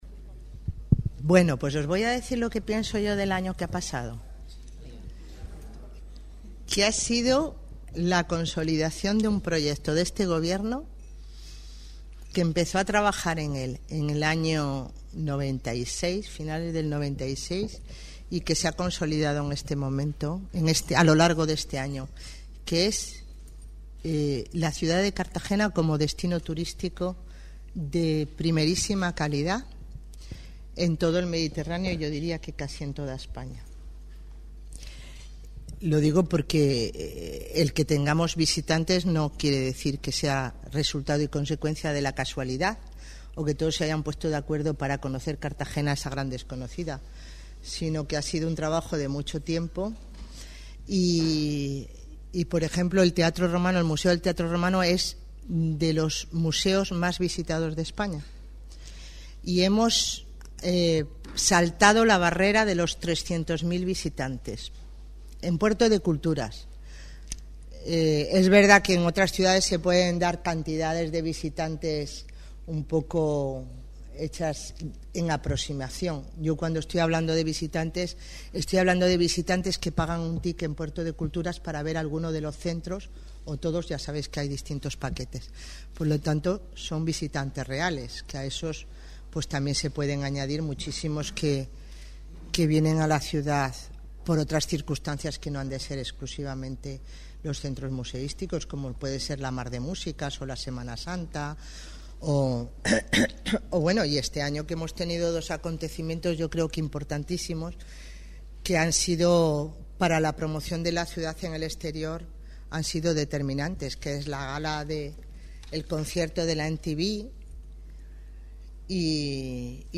Audio: 2009-12-18 Declaraciones alcaldesa en desayuno de Navidad con los medios (MP3 - 18,84 MB)